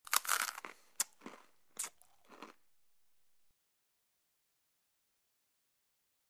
Bite Into Crackers Or Pretzel With Chewing And Lip Smack